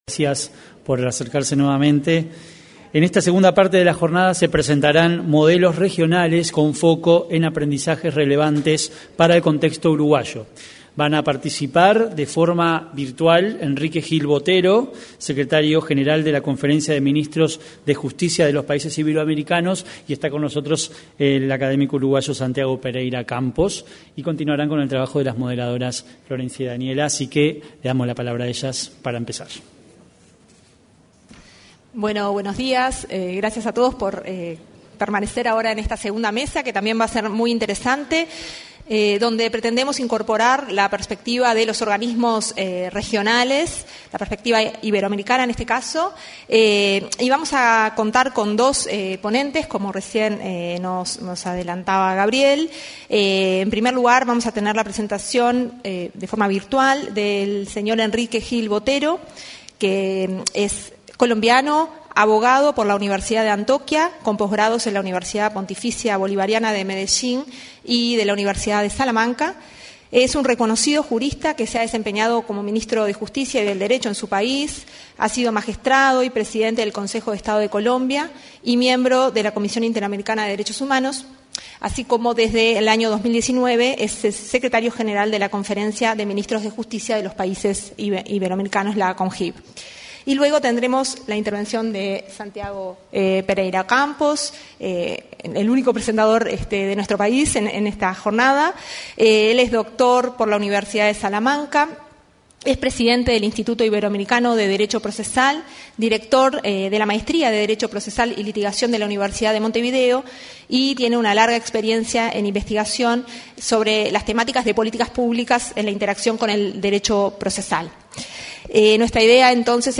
Seminario internacional Hacia un Ministerio de Justicia y Derechos Humanos en Uruguay